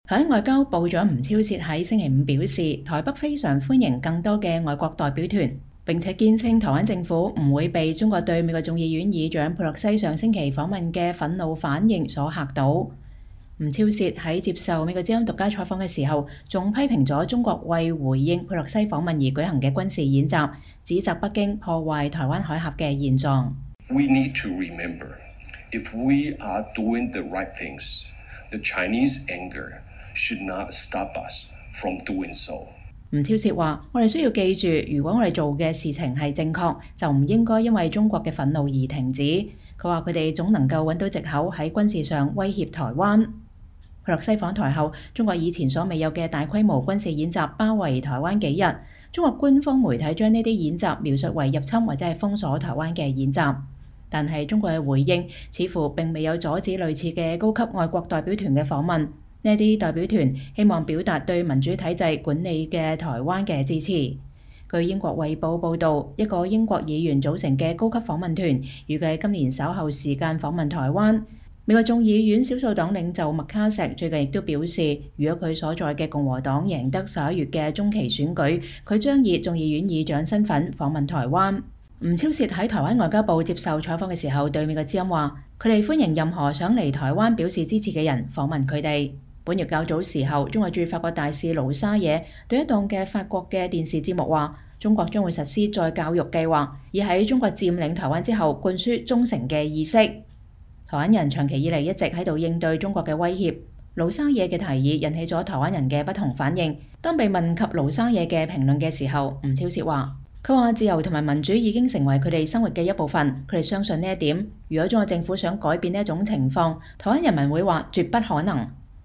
美國之音獨家專訪台灣外長吳釗燮：歡迎更多外國代表團來台